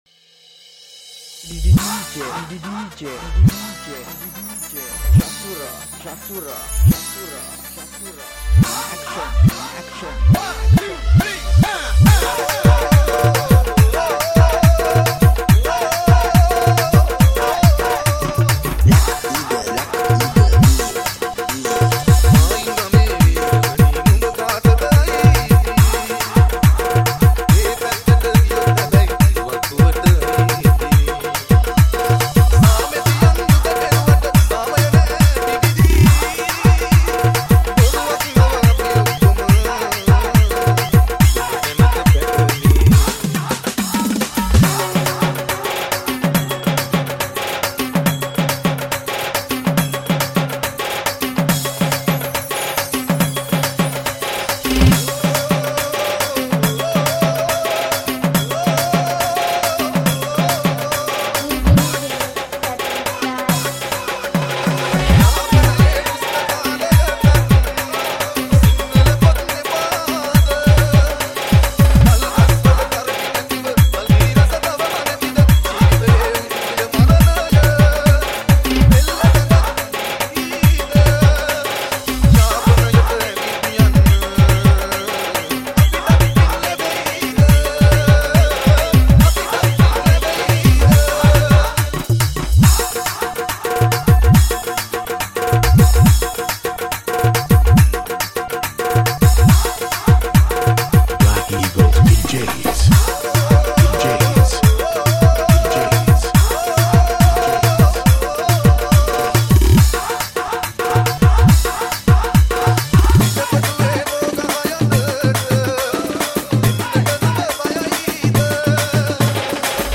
6-8 DANCE DJ REMIX